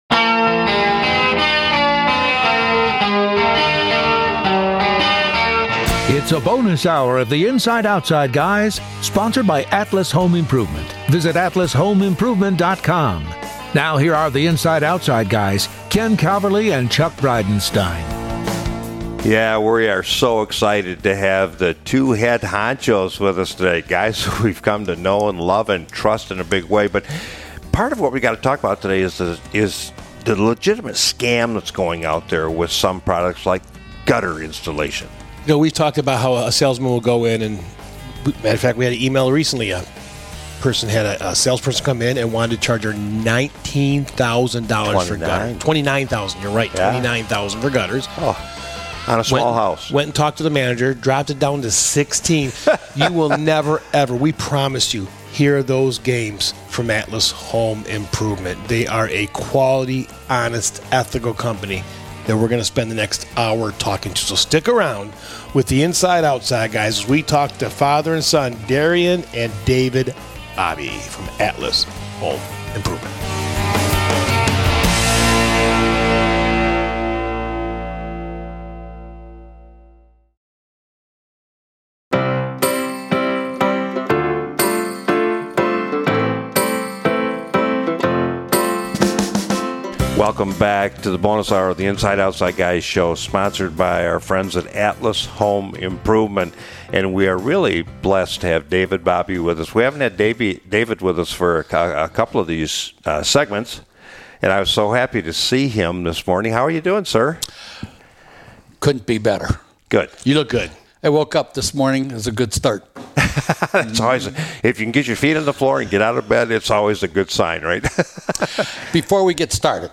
Inside Outside Guys Interview: Importance of Gutters